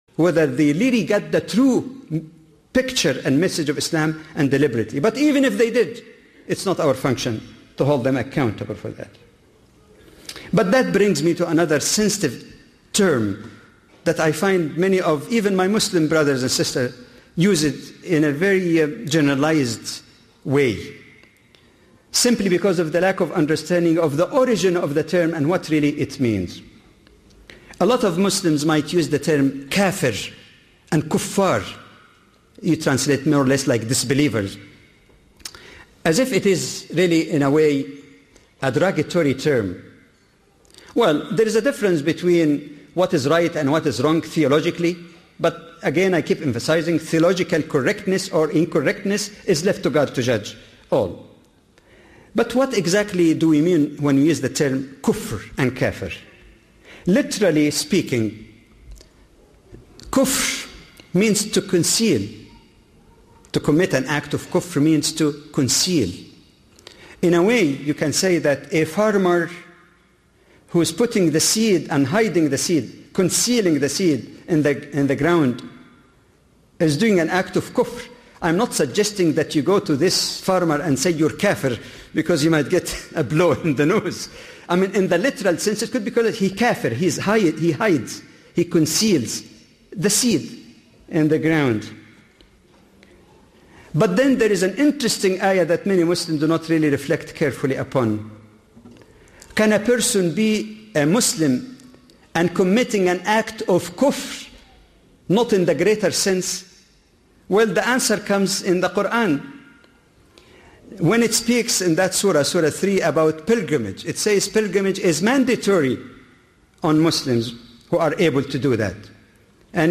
Muslim/Non-Muslim Relations: Commonly Misunderstood Qur’anic Texts – A lecture by Dr. Jamal Badawi.